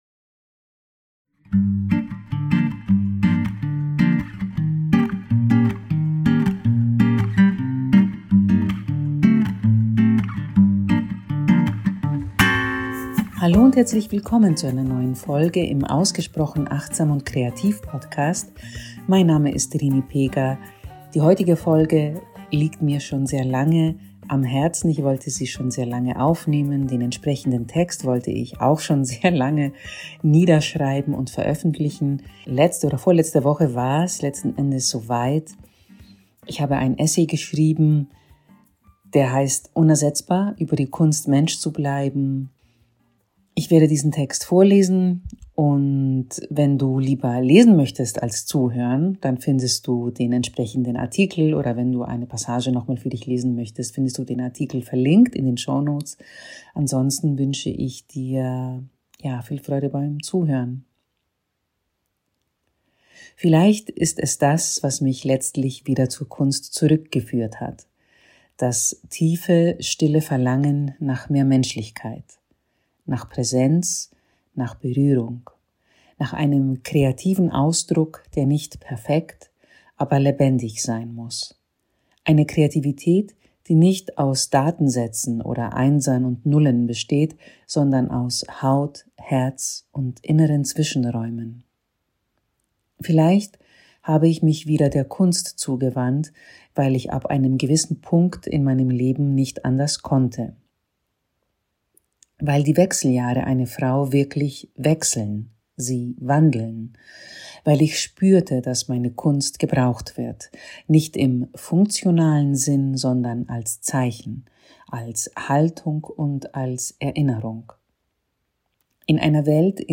Ich teile meine Gedanken über Kunst, Menschlichkeit und all das, was Maschinen niemals übernehmen können. Eine persönliche Reflexion - leise, klar und unbequem.